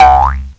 BOING